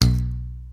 26. 26. Percussive FX 25 ZG